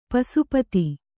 ♪ pasu pati